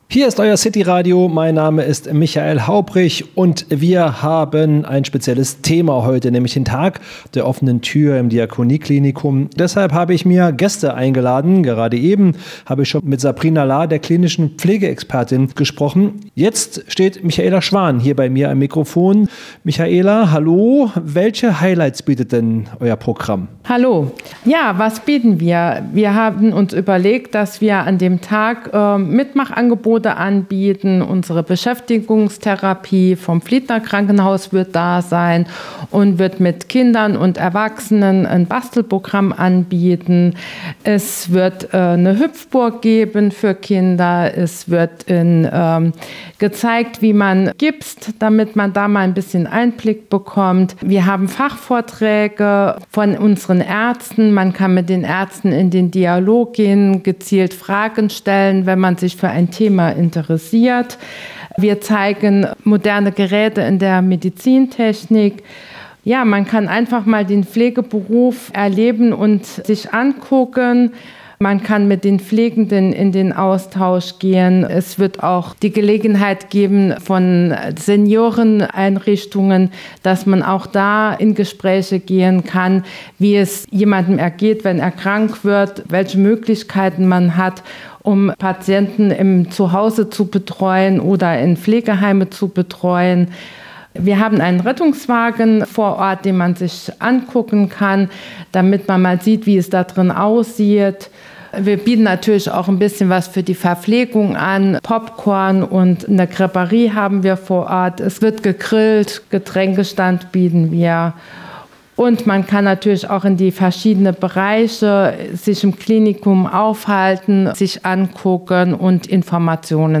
Im Studio von CityRadio Saarland durften wir kürzlich drei spannende Gäste begrüßen: